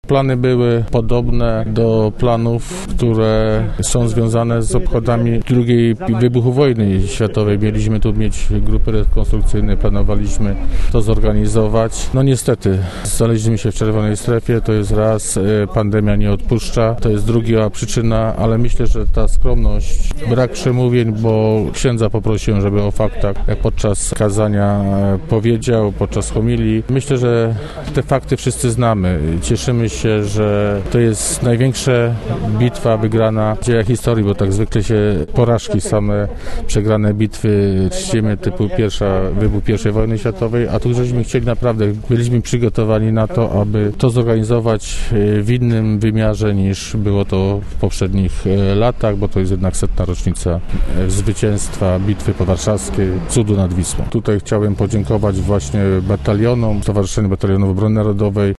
– mówił starosta powiatu wieluńskiego, Marek Kieler.